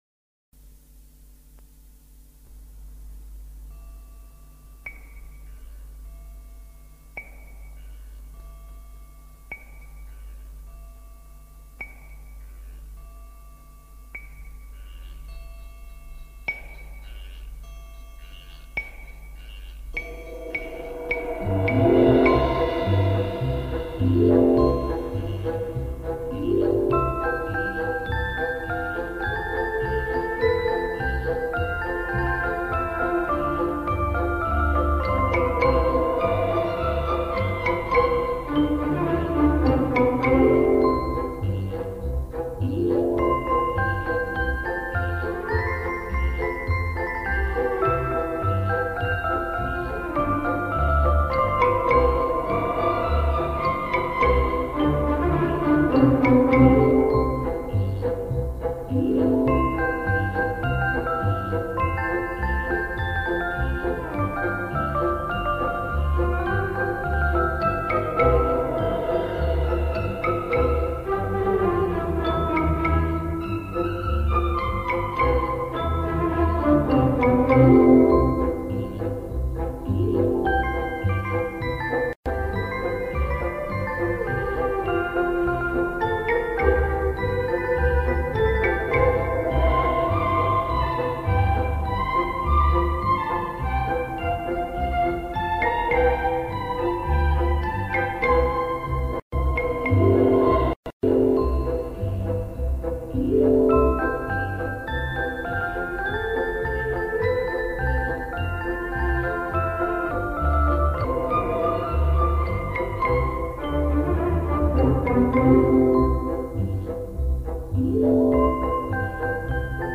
سرود های کودک